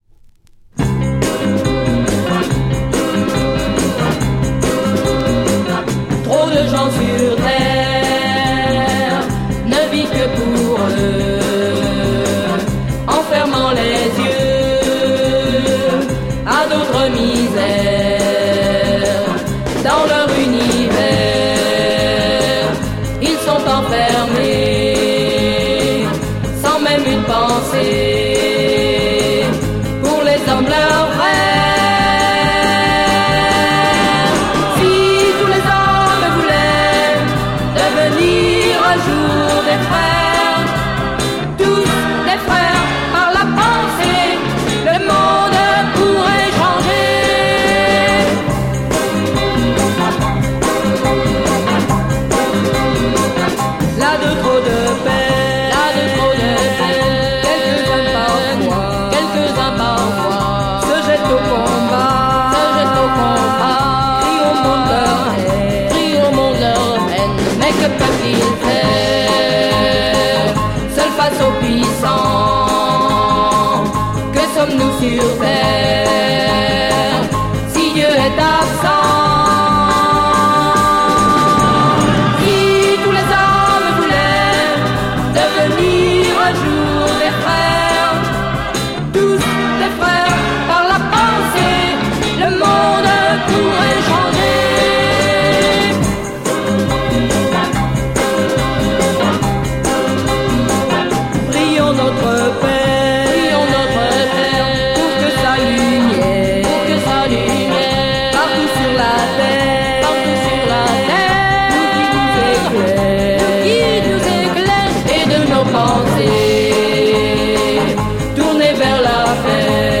Créole female group soul groove EP
Beautiful Female Xian vocal groove soul EP!!